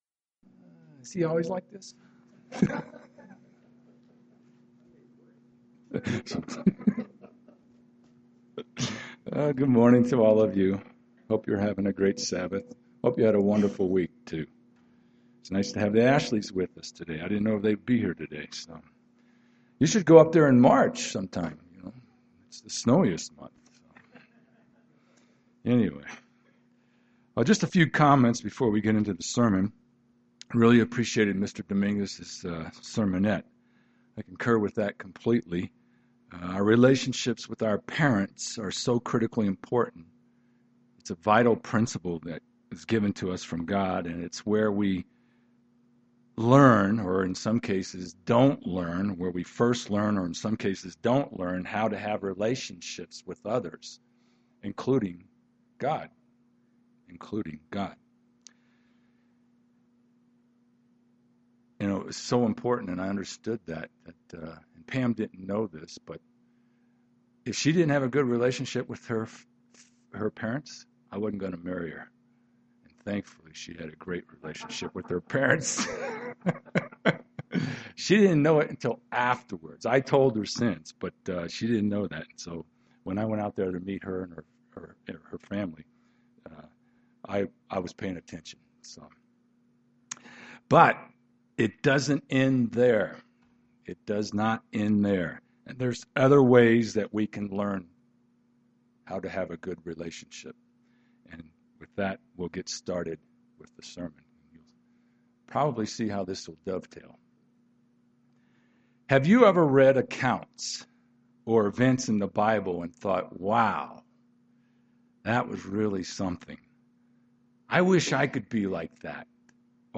Are these goals too lofty for us to aspire to? This sermon looks at some of the keys to improving our relationship with God.
Given in Denver, CO